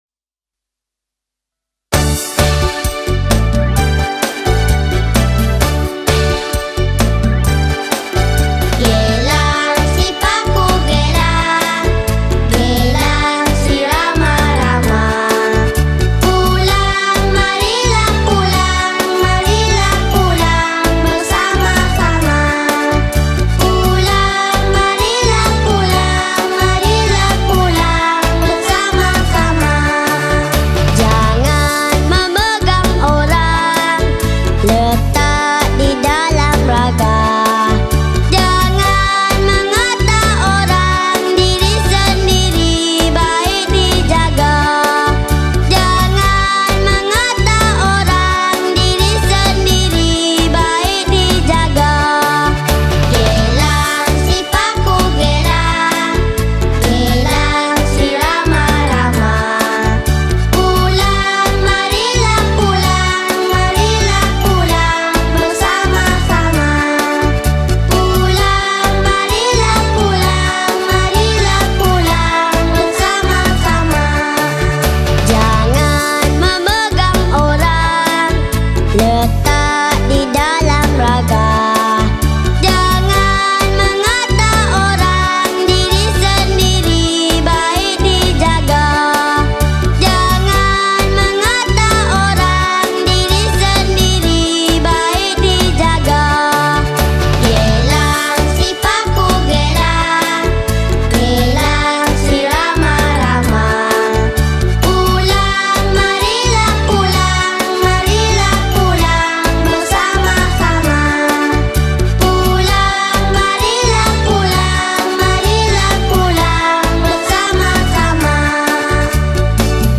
Lagu Kanak-kanak
Nusantara Children Song